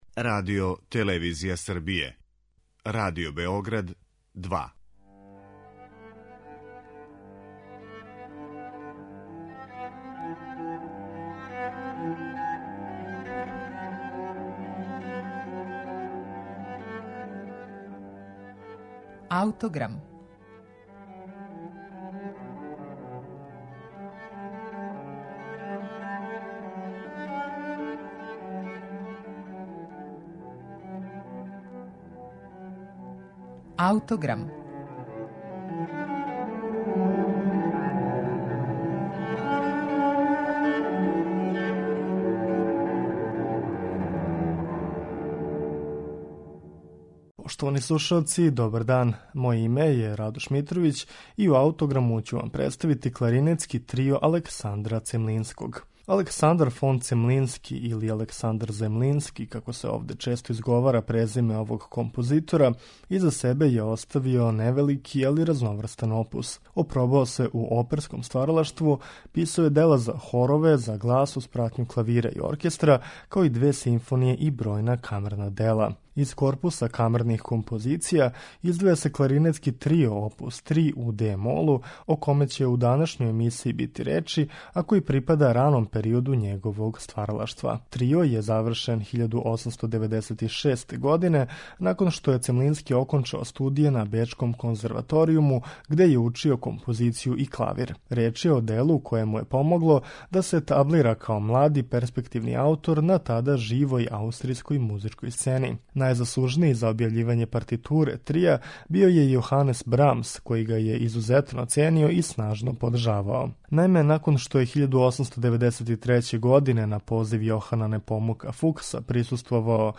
Кларинетски трио опус 3 у де-молу Александра Цемлинског припада раном периоду стваралаштва овог свестраног композитора. Трио је завршен 1896. године, након што је Цемлински окончао студије композиције и клавира на Бечком конзерваторијуму.
Кларинетски трио Александра Цемлинског слушаћете у извођењу трија Beaux Arts.